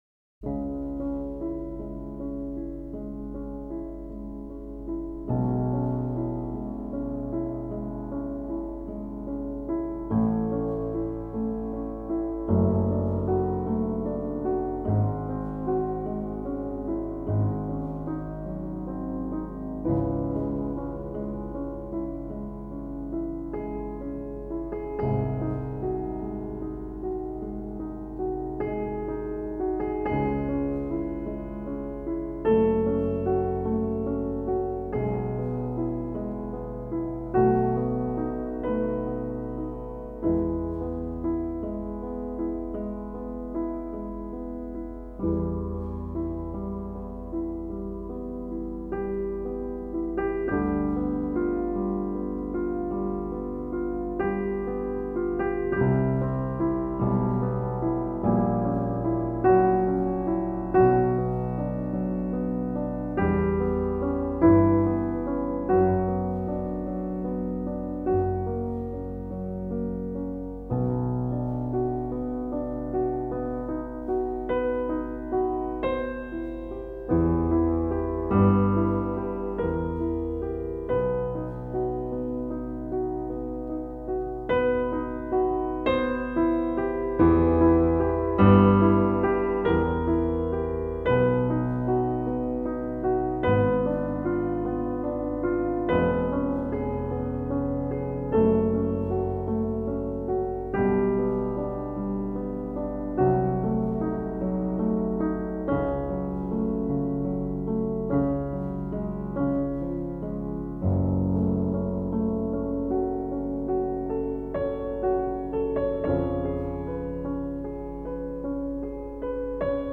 Наконец-то классика появилсь
lyudvig-van-bethoven---pervaya-chast-(lunnaya)-chetyirnadtsatoy-sonatyi-dlya-fortepiano.mp3